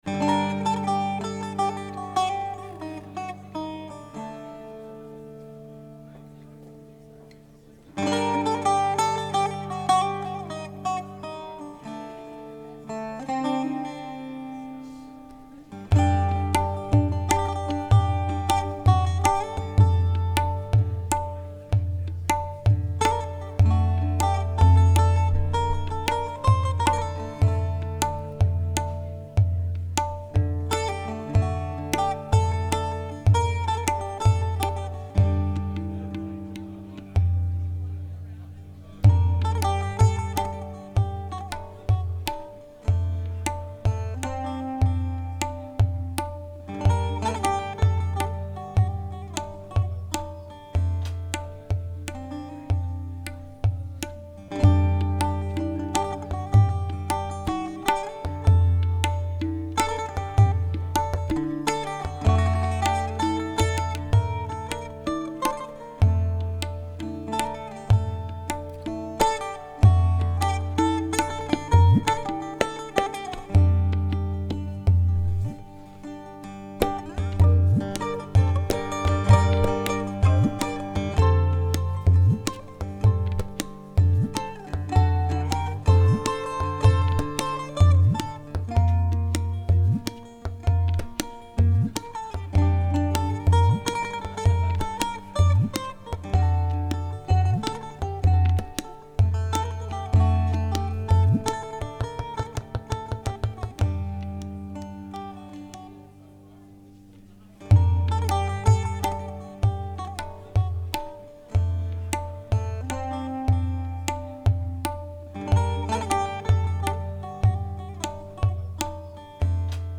Live at Unity College
A beautiful Armenian melody